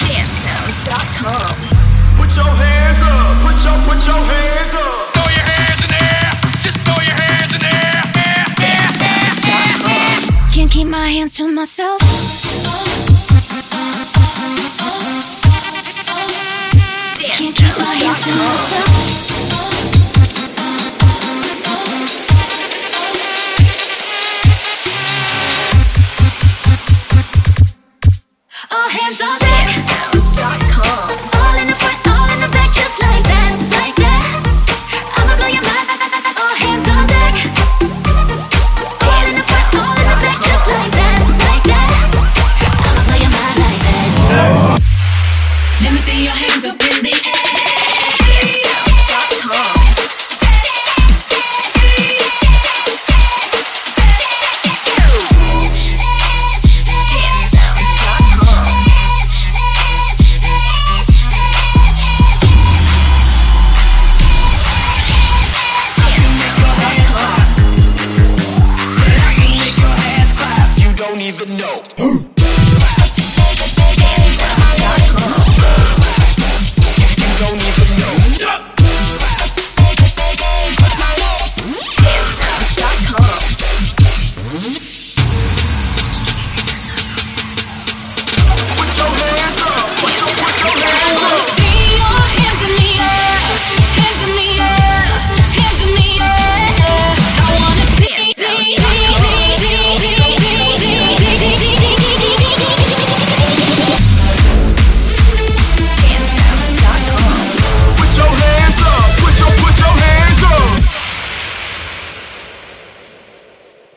Premade Dance Music Mix